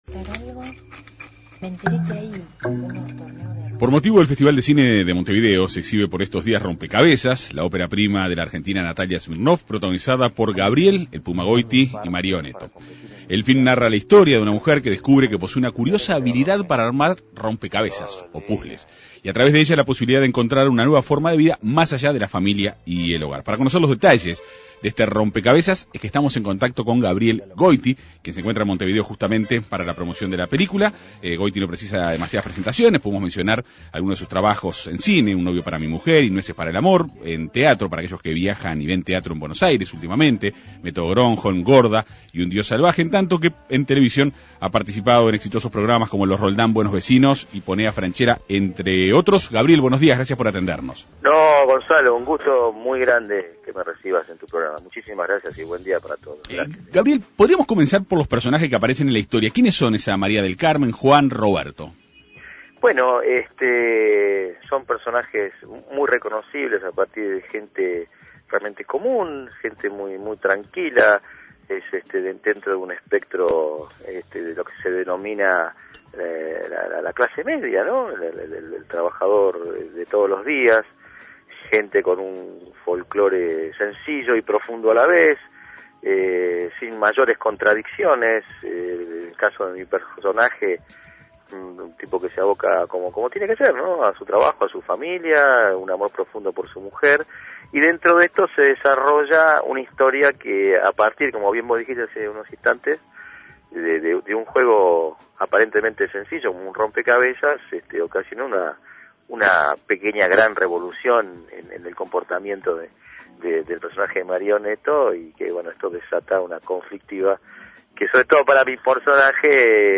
El actor argentino dialogó en la Segunda Mañana de En Perspectiva.